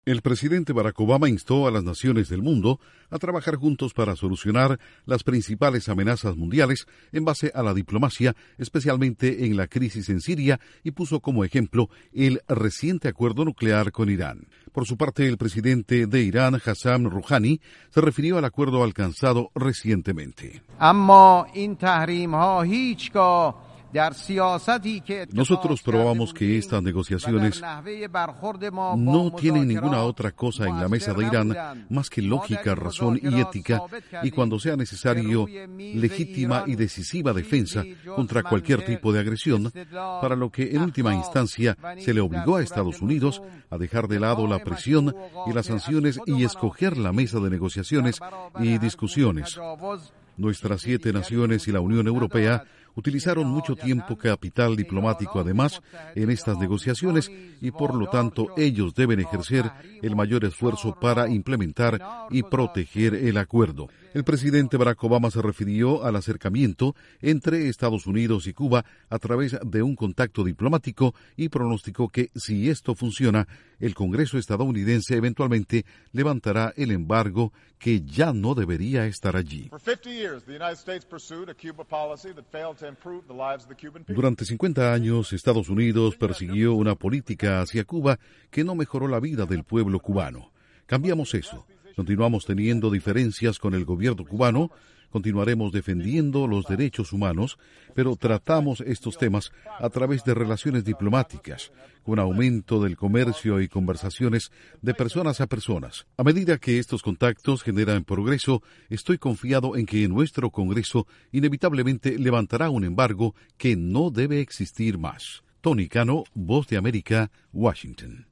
Obama pide unidad en la ONU para poder resolver las principales amenazas mundiales y elogia el uso de la diplomacia para el acercamiento con Cuba. Informa desde la Voz de América en Washington